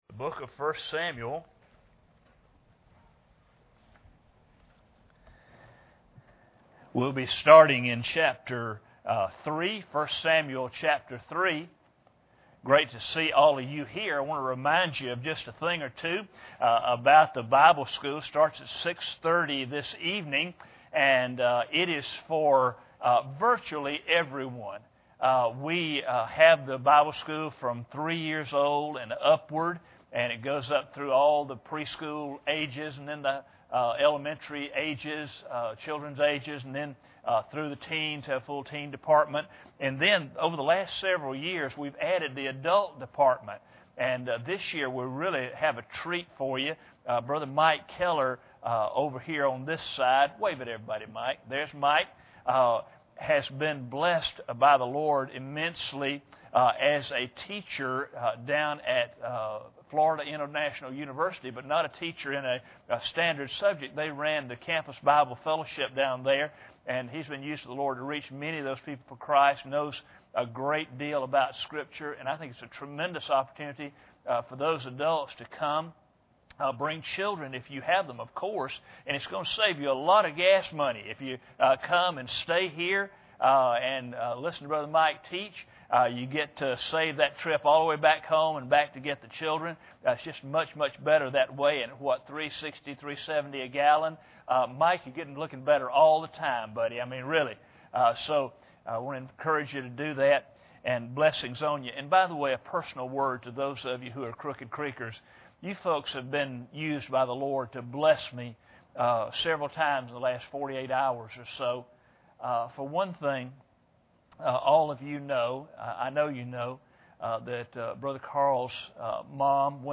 1 Samuel 3:1-4 Service Type: Sunday Morning Bible Text